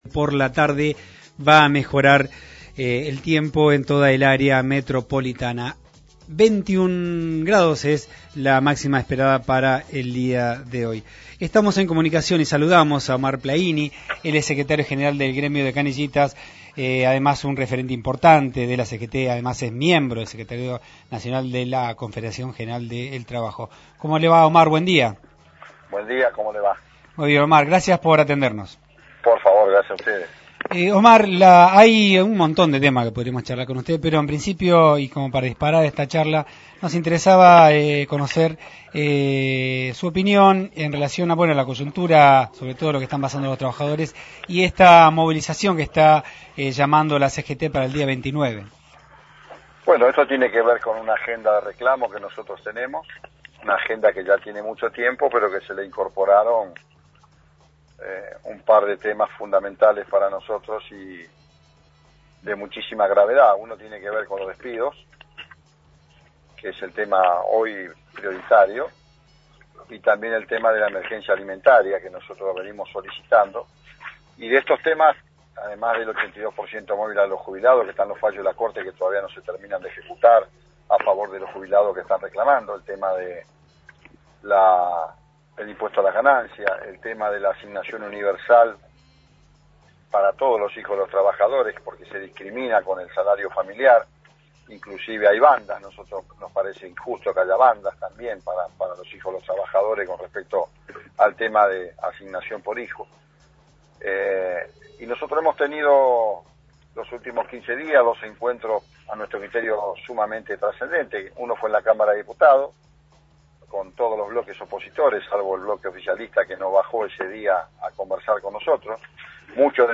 Omar Plaini, diputado del bloque Cultura, Educación y Trabajo y secretario General del Sindicato de Canillitas, adelantó en los micrófonos de La Tecno, al aire de Primer Acto que las cinco centrales sindicales realizarán movillizaciones multitudinaria el 29 de abril próximo, en rechazo de los despidos en los sectores público y privado.